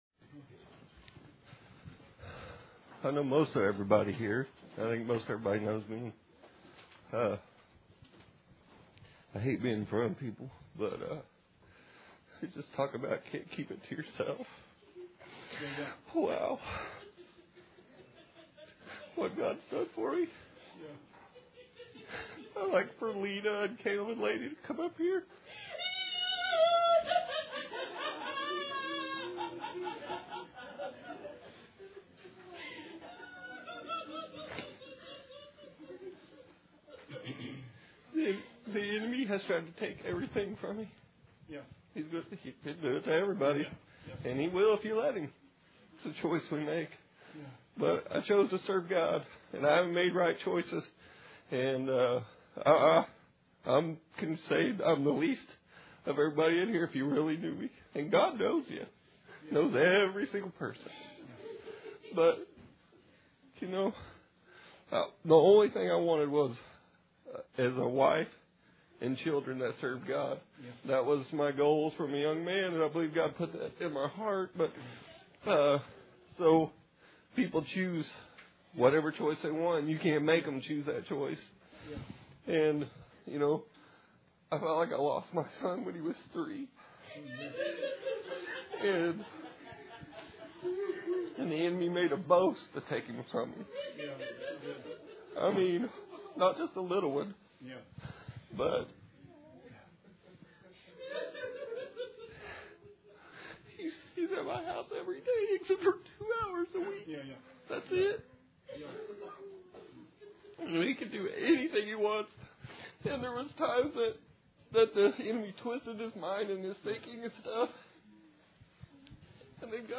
Sermon 9/25/16